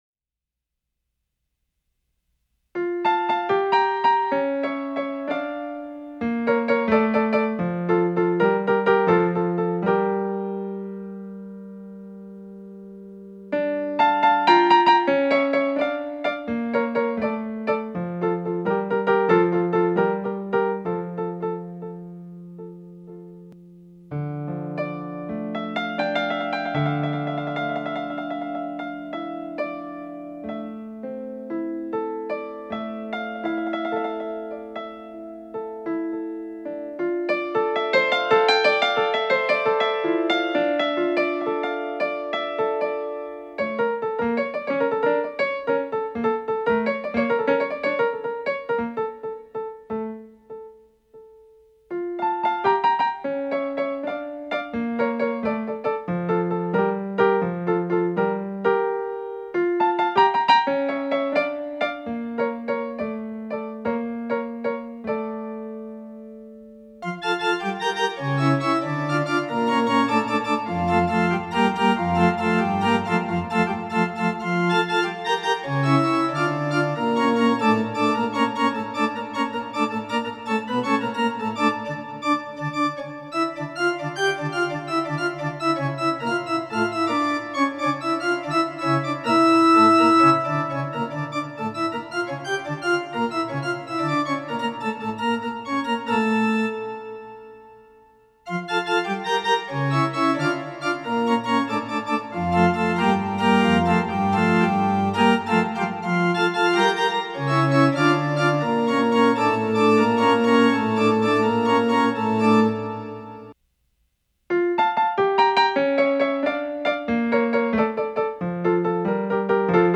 Comme Un Signal (piano et orgues) 12.01.27 D
Cette pièce musicale est calquée sur le rythme des mots utilisés.